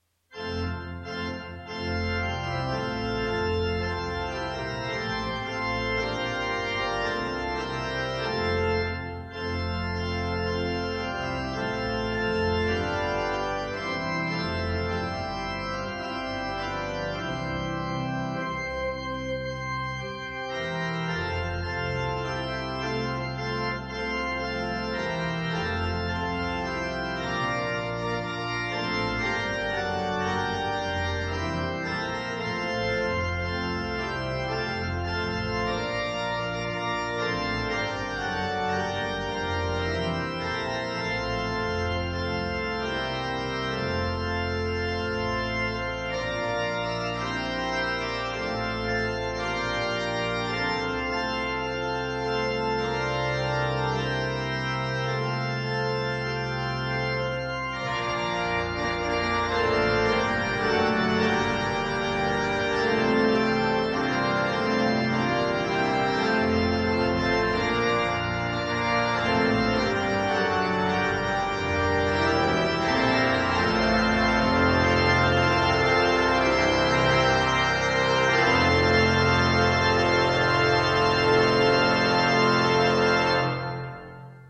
By Organist/Pianist